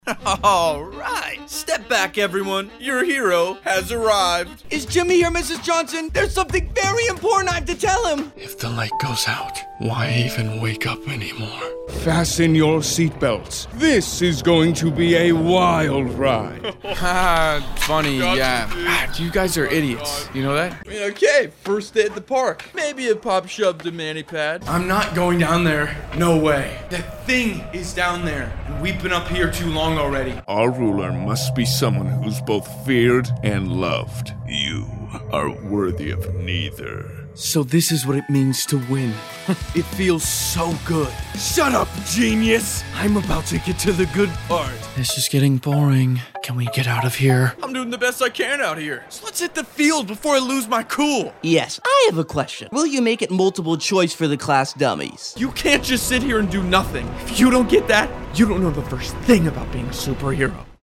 Actor and Voice Actor
Character Demo